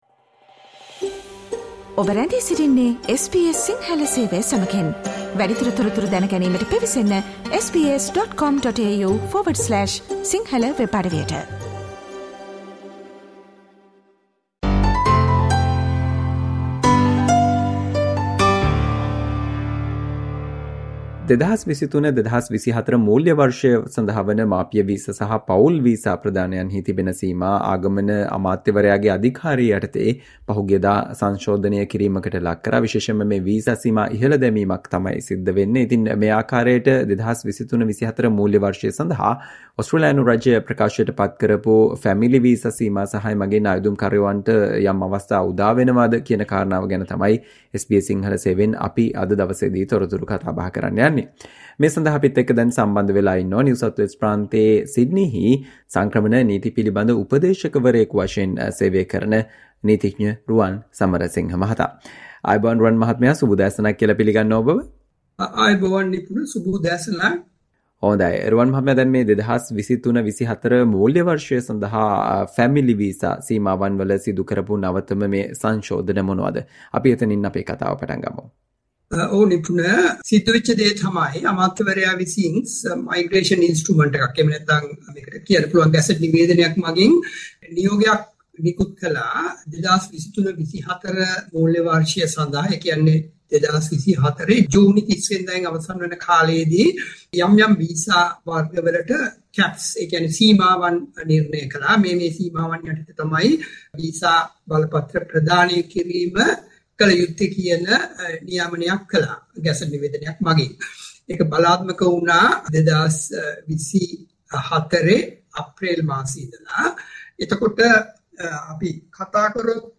SBS Sinhala discussion on new Family Visa Caps Announced by Australian Government for the 2023-24 financial year